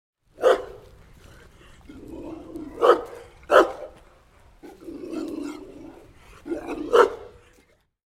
Large Dog Barking And Growling Sound Effect
A huge aggressive dog growls and barks unpleasantly on the street. This realistic sound effect captures the tension and fear of an angry guard dog.
Genres: Sound Effects
Large-dog-barking-and-growling-sound-effect.mp3